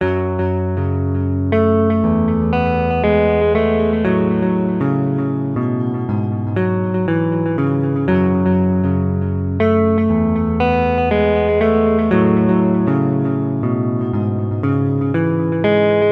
吉他闪闪发光
描述：一个简短的琶音Stratocaster段落，通过Ableton效果器（我想是Ambient和Evolving套装）处理。
标签： 的Ableton 混响 延迟 吉他
声道立体声